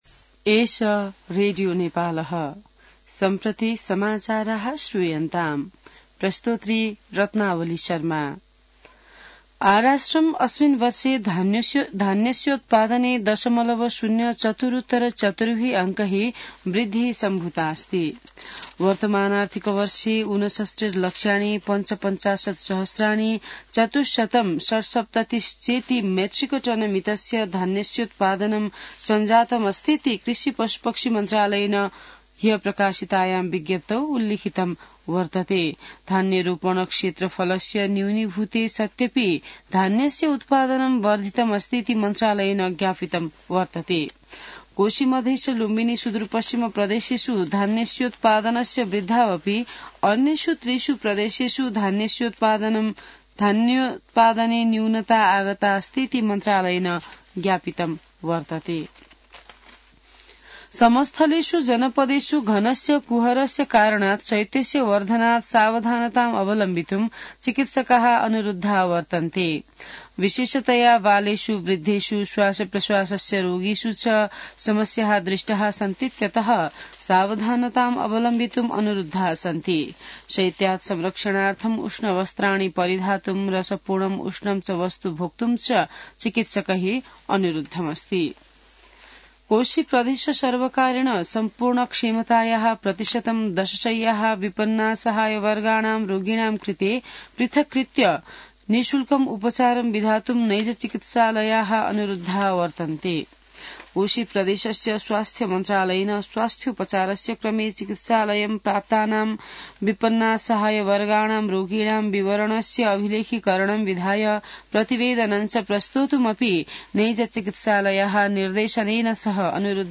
An online outlet of Nepal's national radio broadcaster
संस्कृत समाचार : २१ पुष , २०८१